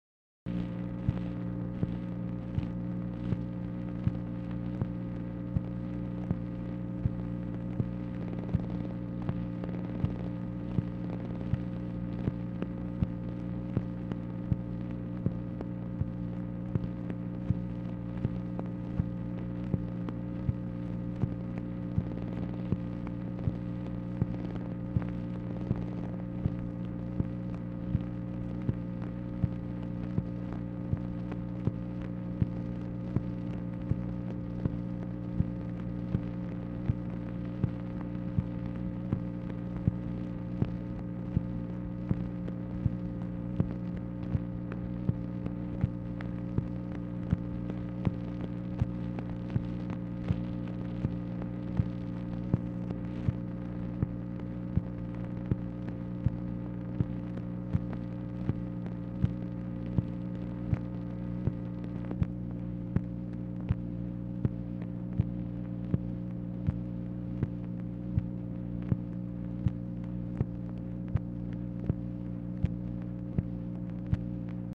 Telephone conversation # 11166, sound recording, MACHINE NOISE, 12/20/1966, time unknown | Discover LBJ
Format Dictation belt
Location Of Speaker 1 LBJ Ranch, near Stonewall, Texas